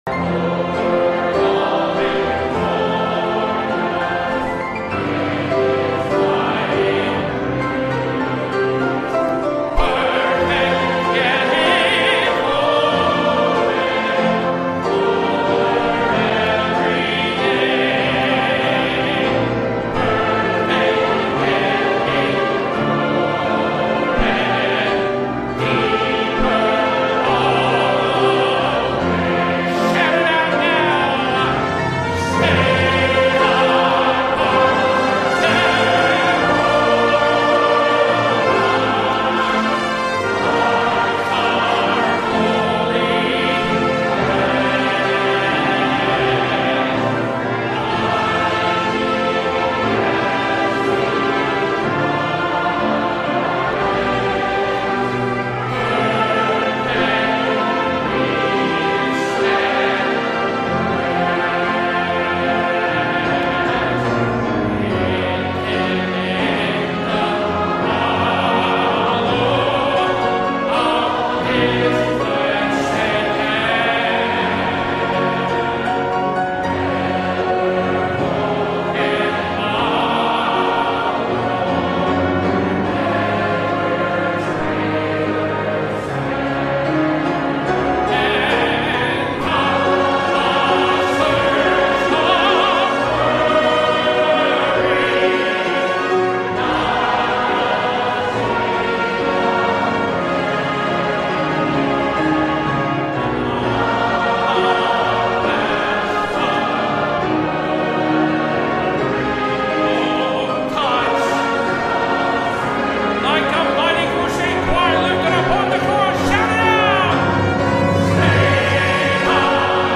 Live Sunday Morning Services